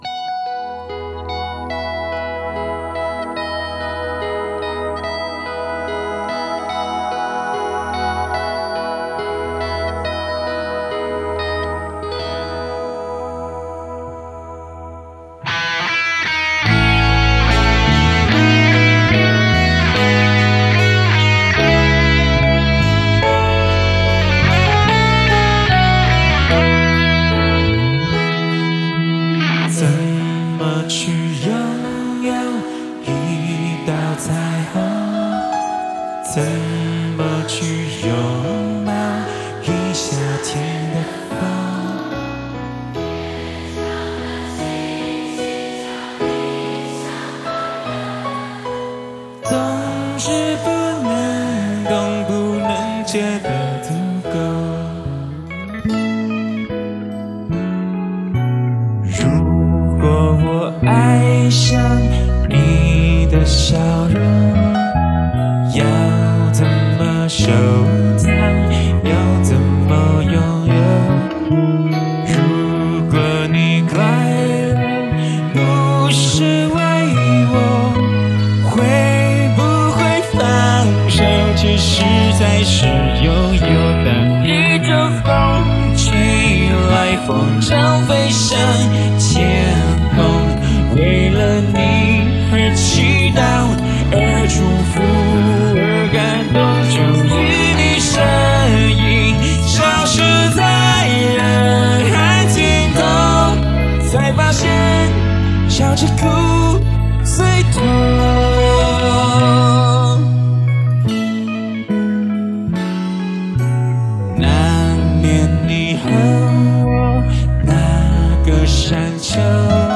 无鼓伴奏